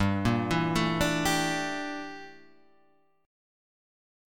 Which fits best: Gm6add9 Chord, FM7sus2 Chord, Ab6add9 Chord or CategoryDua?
Gm6add9 Chord